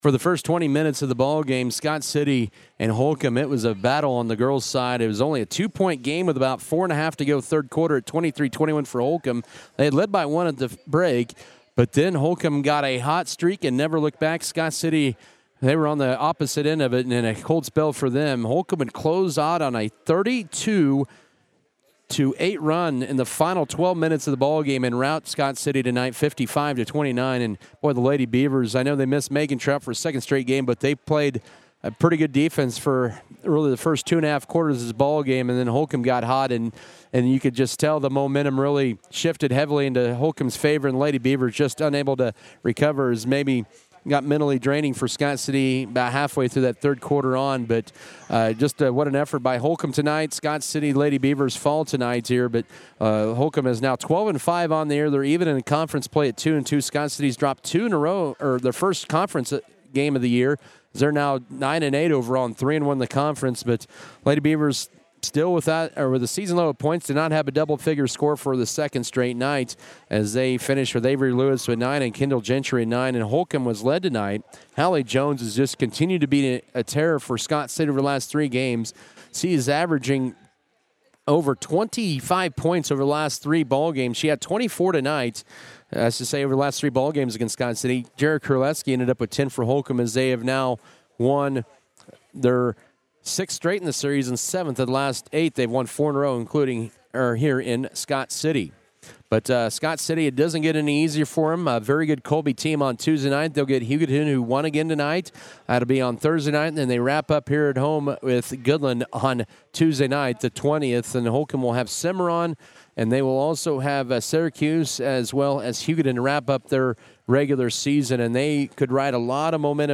Girls Audio Recap